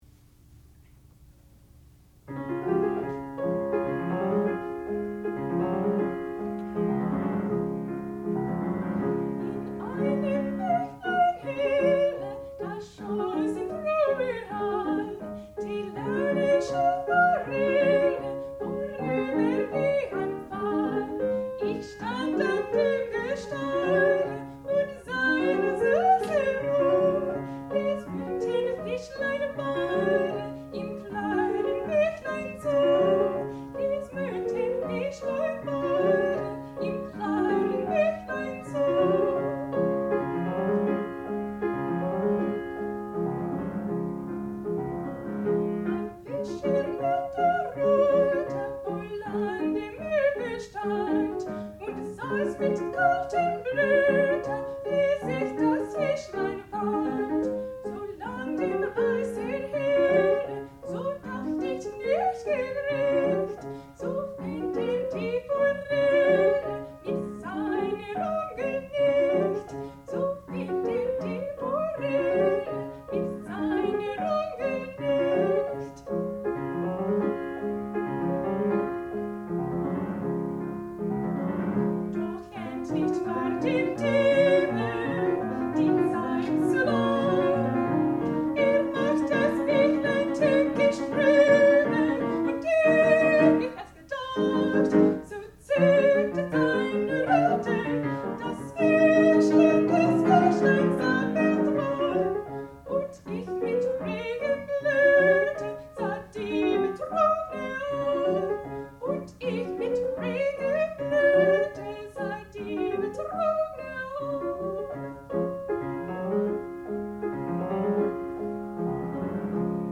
sound recording-musical
classical music
piano
Student Recital
soprano